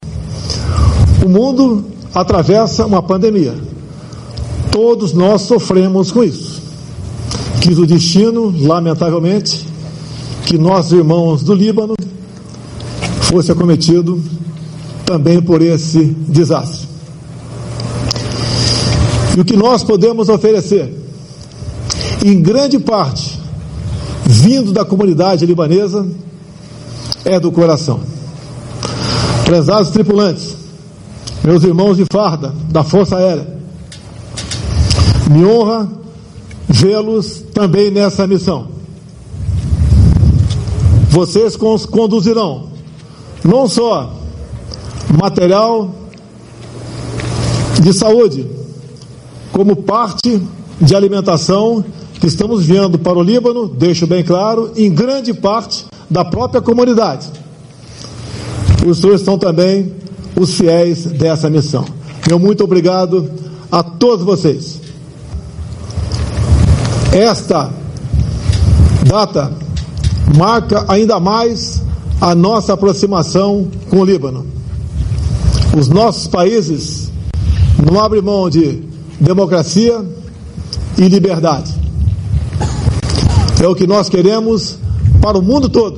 O presidente Jair Bolsonaro participou, nesta manhã de quarta-feira, 12, de solenidade da missão brasileira de ajuda ao Líbano.
Em discurso, Bolsonaro falou sobre a missão.